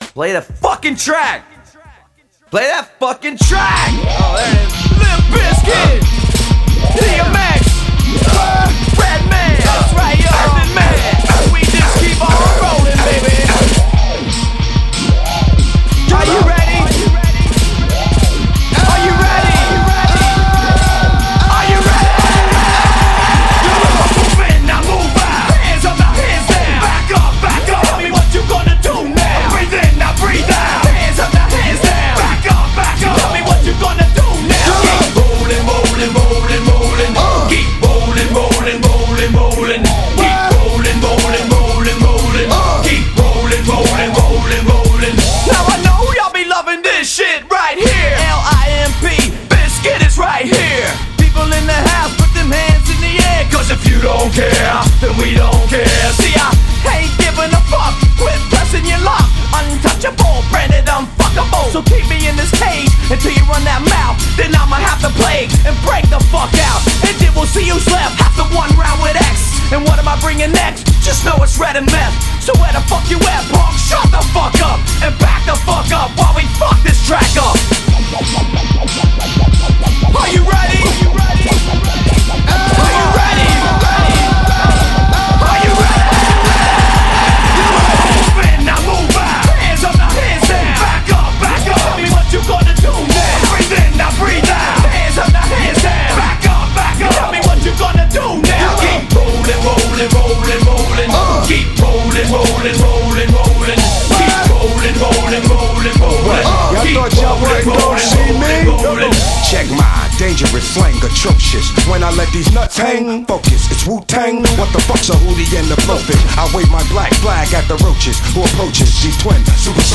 HipHop 2000er